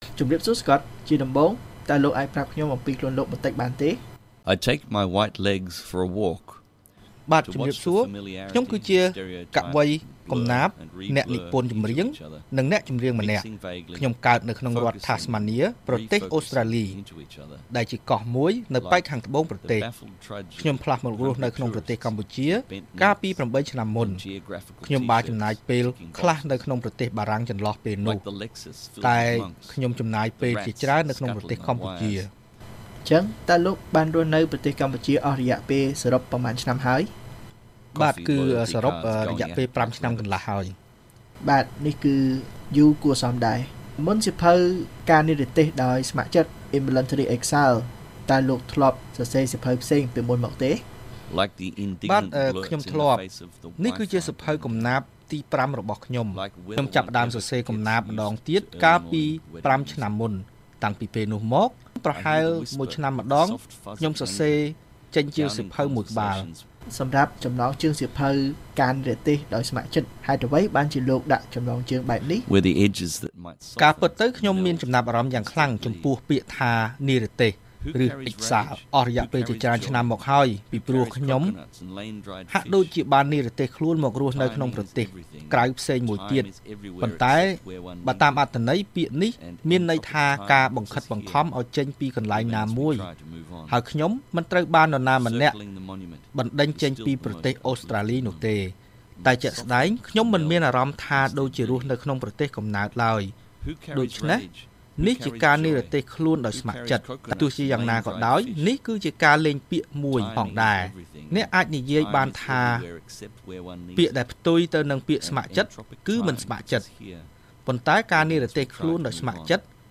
បទសម្ភាសន៍VOA៖ កវីអូស្ត្រាលី និពន្ធកំណាព្យរៀបរាប់ពីជីវិតរស់នៅប្រចាំថ្ងៃរបស់ប្រជាជនកម្ពុជា